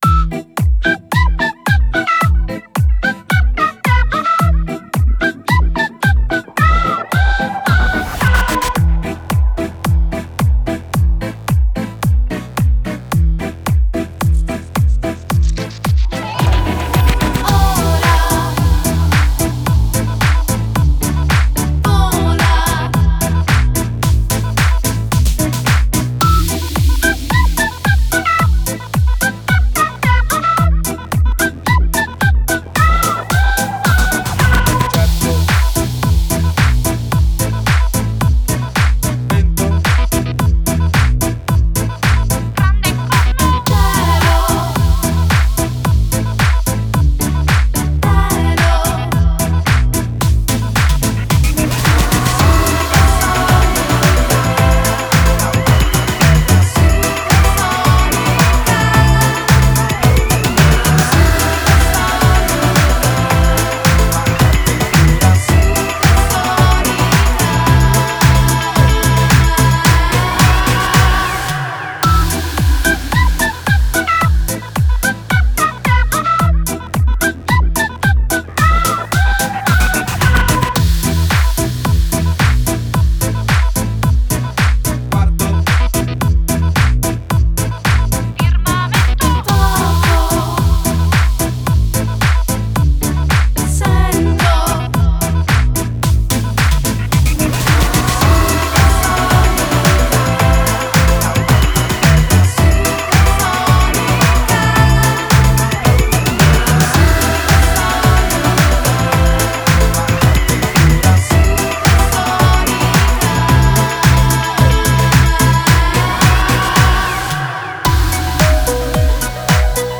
con 15 canzoni tutte ballabili